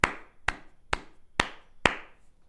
clap02.mp3